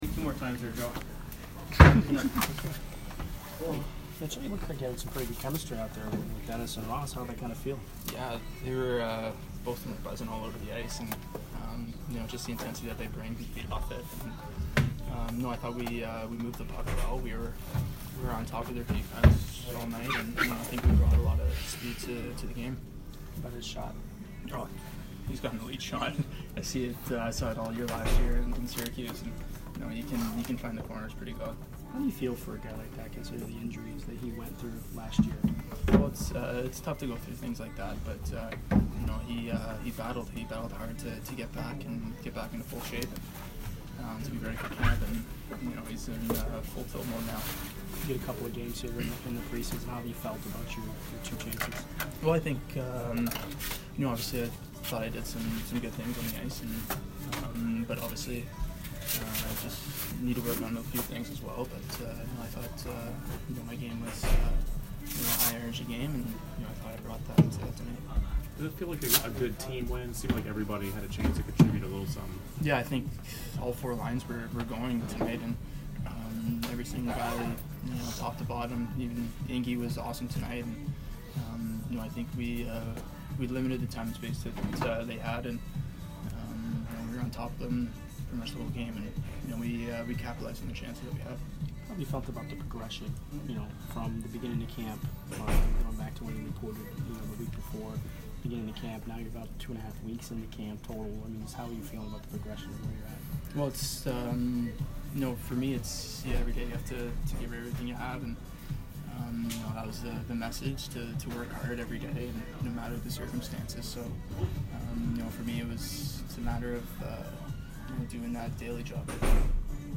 Mitchell Stephens post-game 9/22